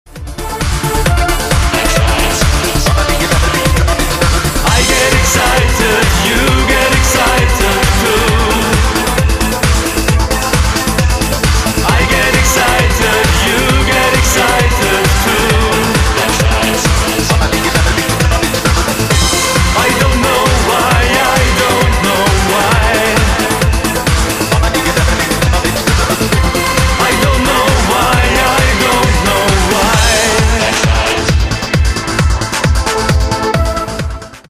• Качество: 128, Stereo
ритмичные
мужской вокал
громкие
Synth Pop
dance
Electronic
электронная музыка